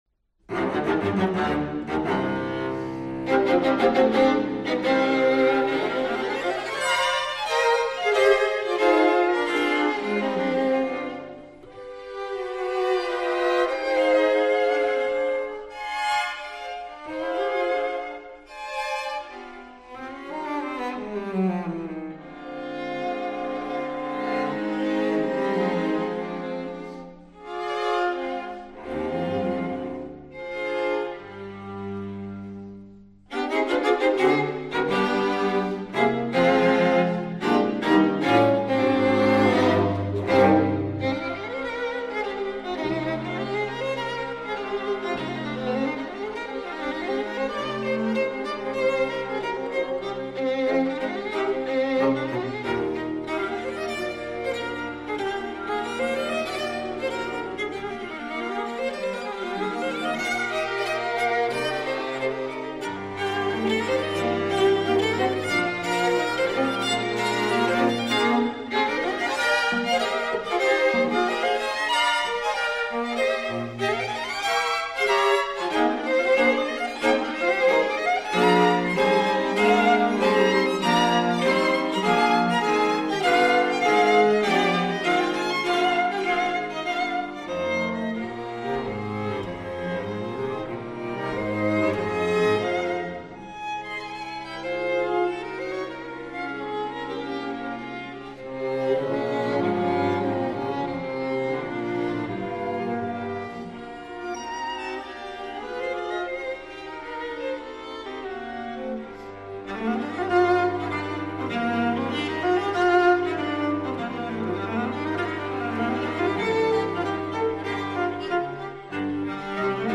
Soundbite 4th Movt